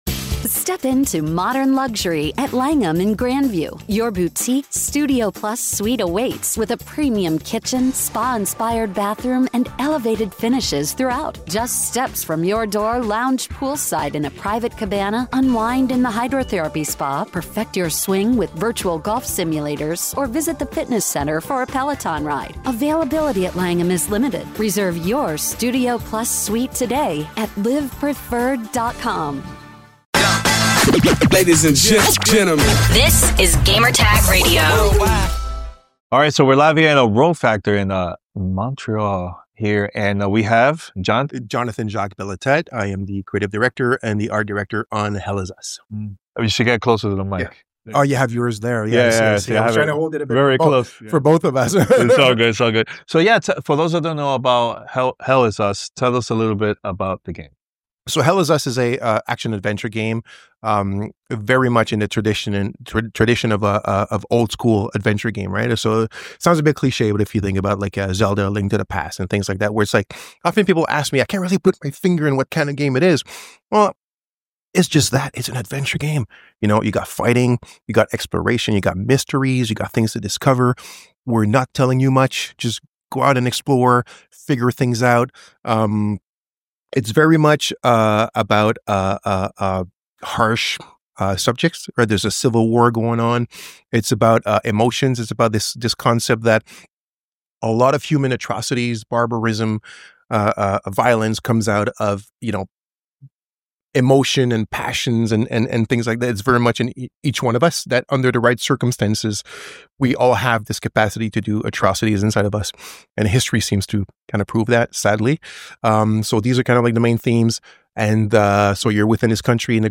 Hell Is Us Interview with Rogue Factor